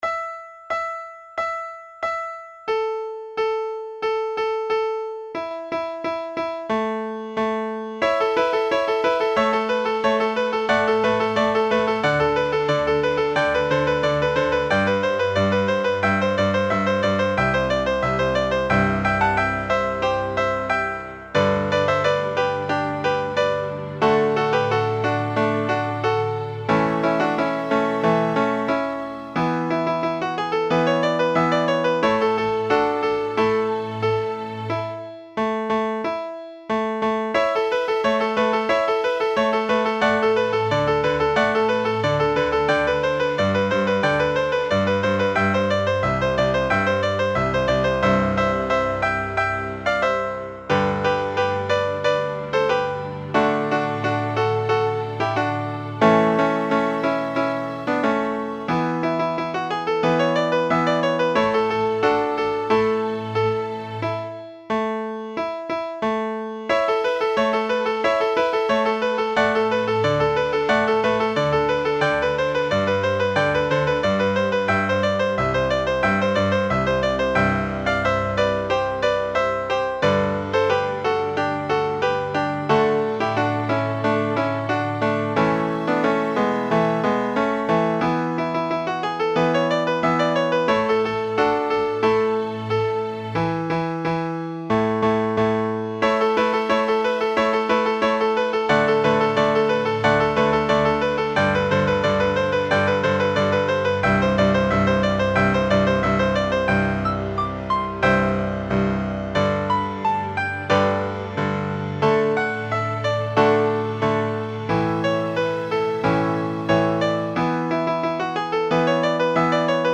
String Practice Pieces